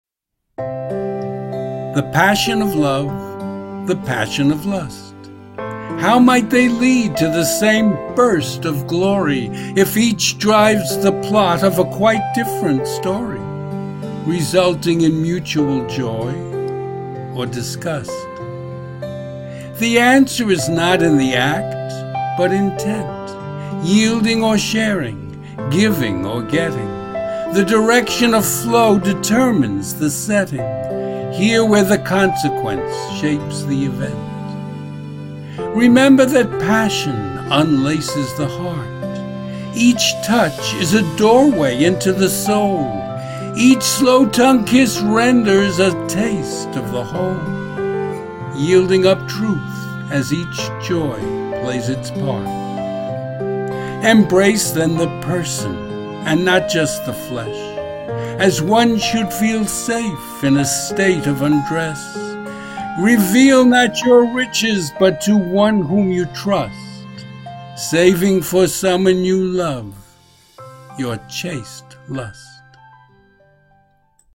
Audio and Video Music:
Emotional Love Theme.